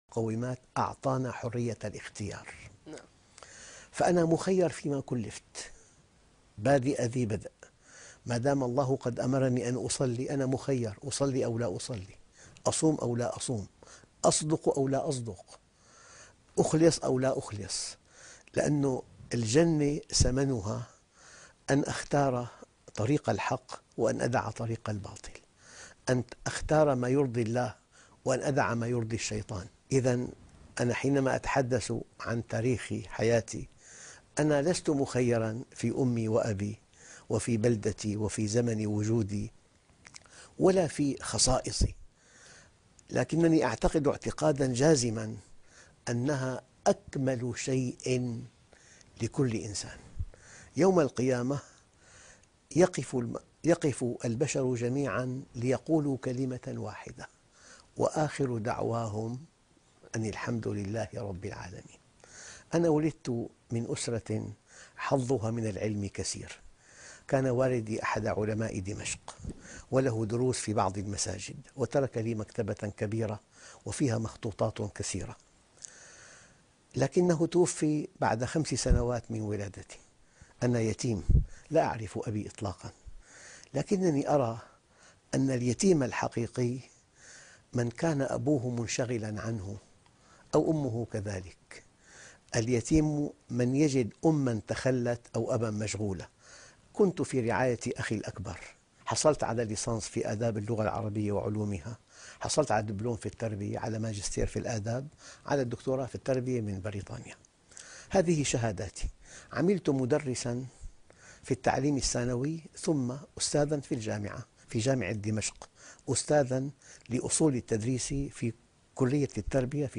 هل الإنسان مخير أم مسير - لقاء وحوار خاص - الشيخ محمد راتب النابلسي